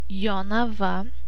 pronunciation) is the ninth largest city in Lithuania with a population of c. 30,000.[2][3][4] It is located in Kaunas County in central Lithuania, 30 km (19 mi) north east of Kaunas, the second-largest city in Lithuania.[3] It is served by Kaunas International Airport.